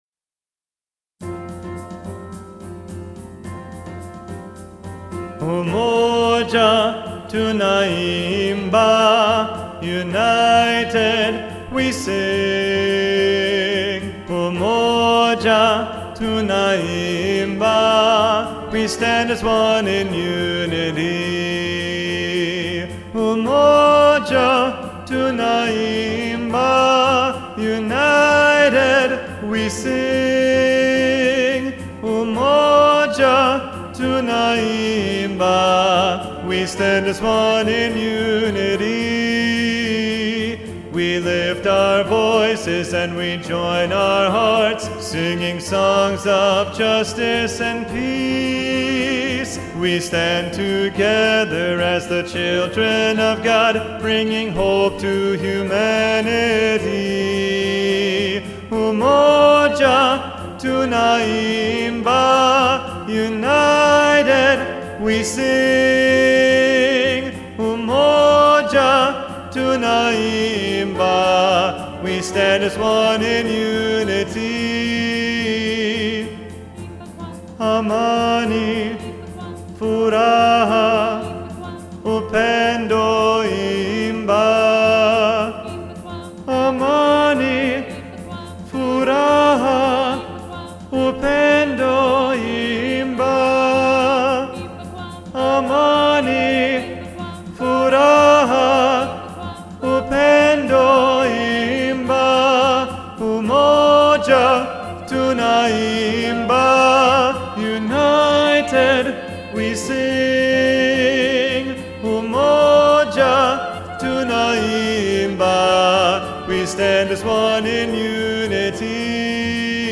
3-Part Mixed – Part 3a Predominant